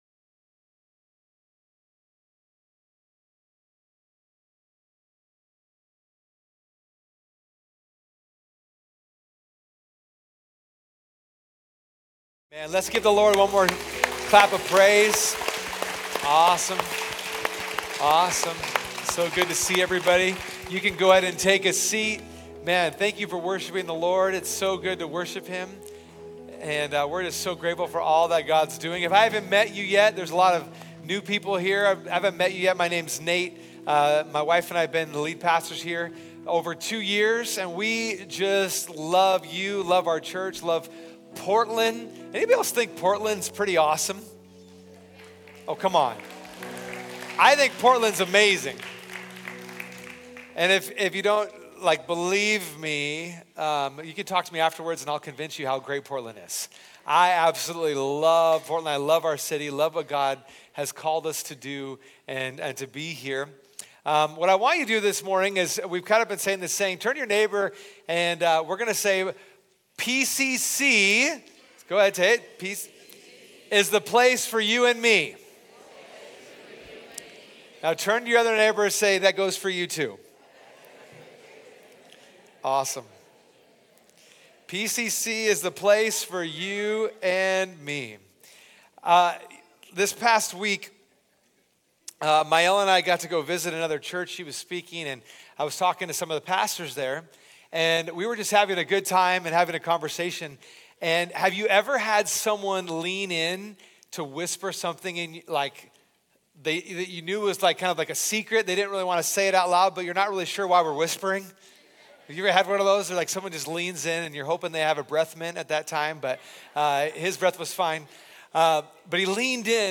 Sunday Messages from Portland Christian Center Politics | HOT TOPICS | Part 4 Sep 22 2024 | 00:43:06 1x 00:00 / 00:43:06 Subscribe Share Spotify RSS Feed Share Link Embed